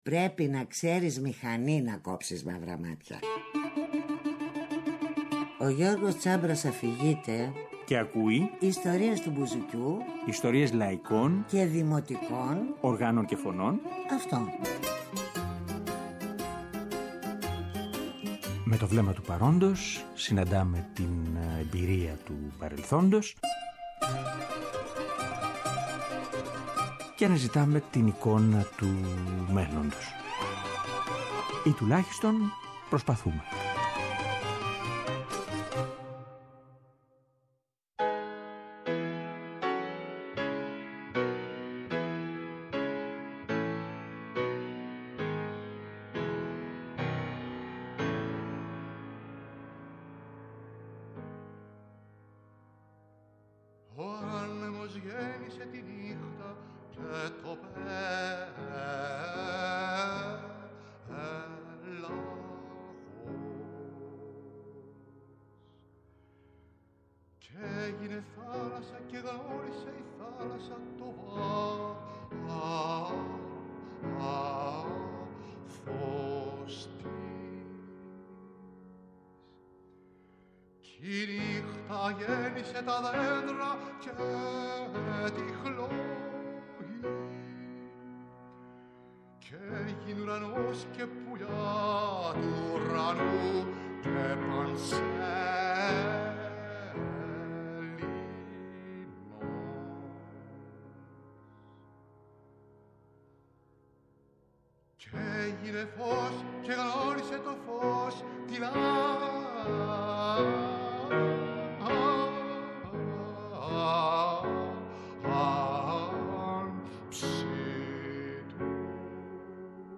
Αφιερώματα Μουσική